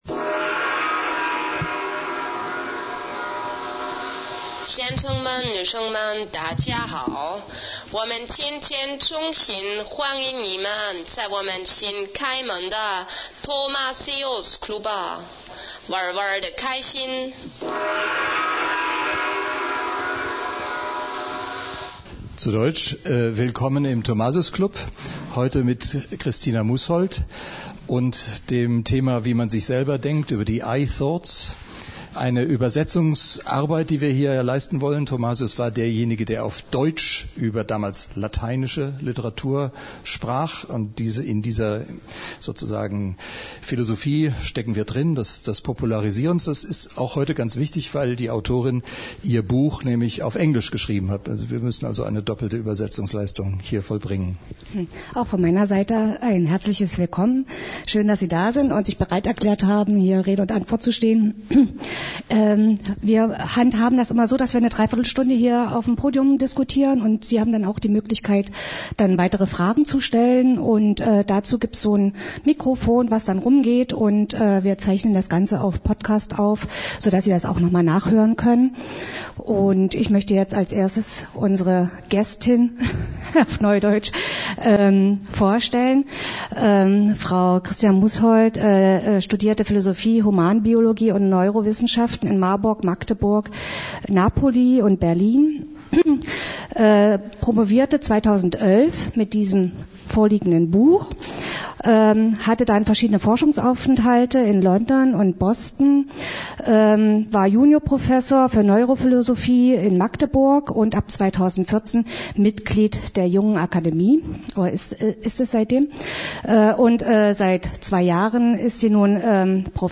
18. Oktober 2017 (20:00 Uhr – Café Alibi)